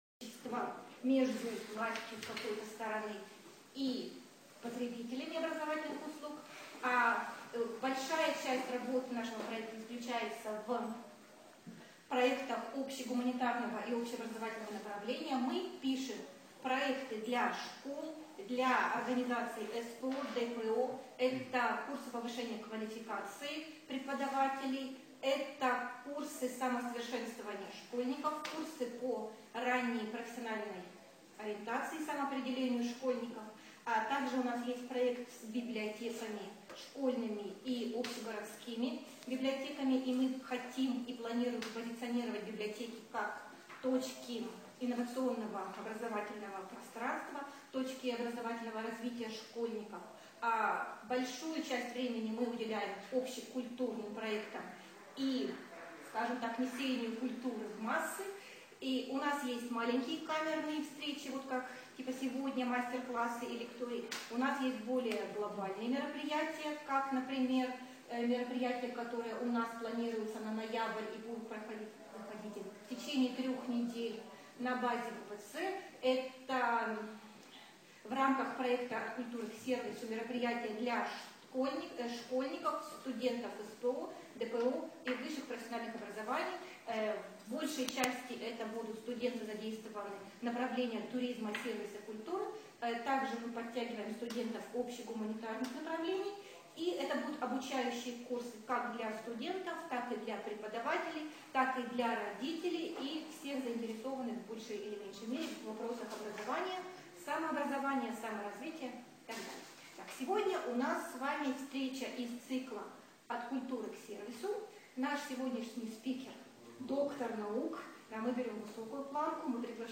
Аудиокнига Зачем людям нужно искусство?